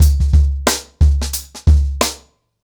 TrackBack-90BPM.31.wav